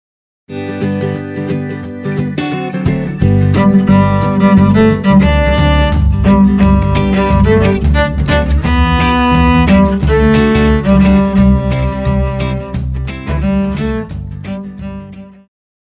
Pop
Cello
Band
Instrumental
Rock
Only backing